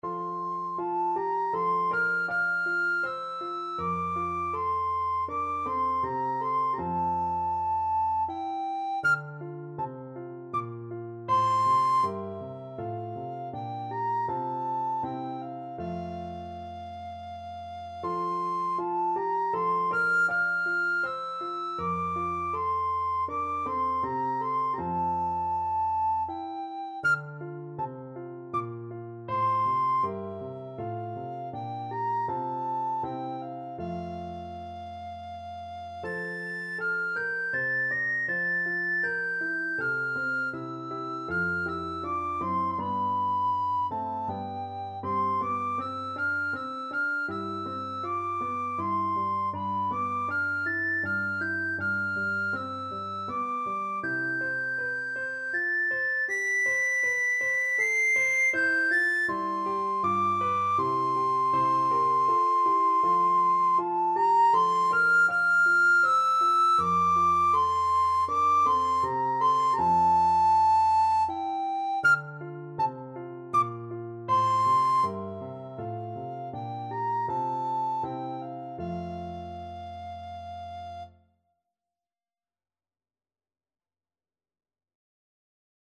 Classical Gluck, Christoph Willibald Dance of Blessed Spirits from Orfeo and Euridice Soprano (Descant) Recorder version
Recorder
3/4 (View more 3/4 Music)
F major (Sounding Pitch) (View more F major Music for Recorder )
Andante
Classical (View more Classical Recorder Music)